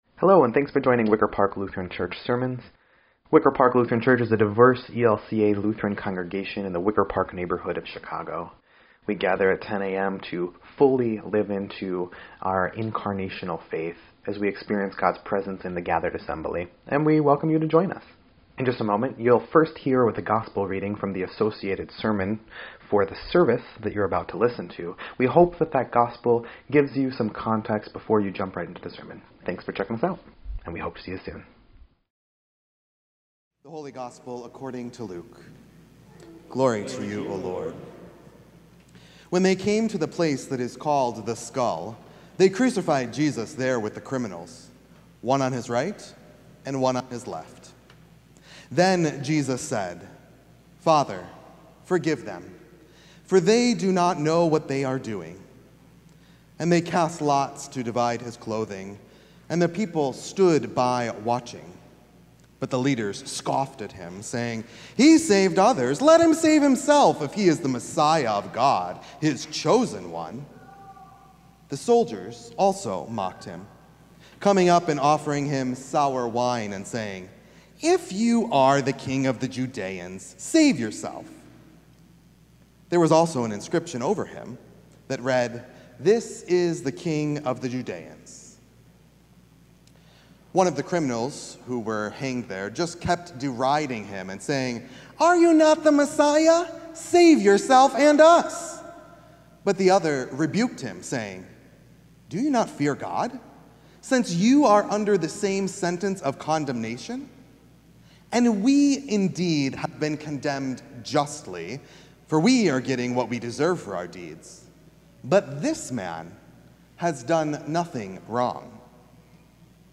These are sermons preached at Wicker Park Lutheran Church in Chicago, IL.